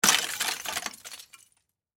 На этой странице собраны разнообразные звуки сосулек — от нежного звона капель до резкого обрыва ледяных глыб.
Звук падающей сосульки, разбившейся о землю